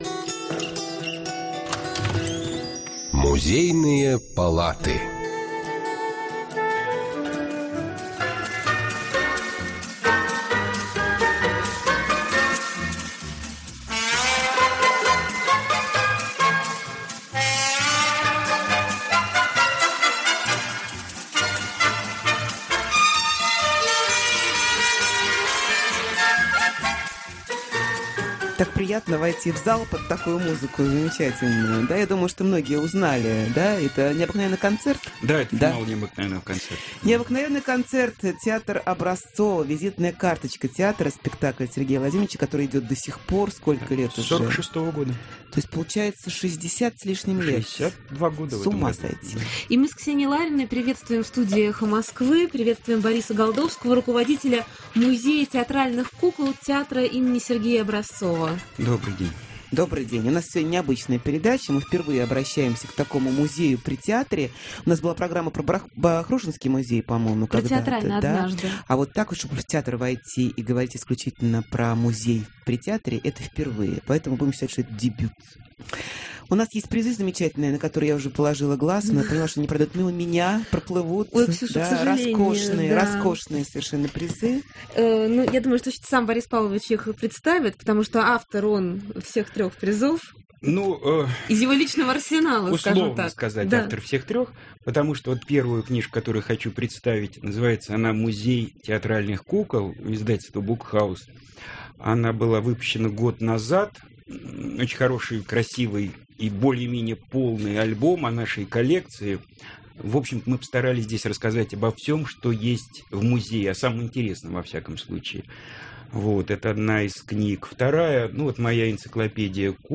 2008-03-15 Скачать (Звучит музыка) К.ЛАРИНА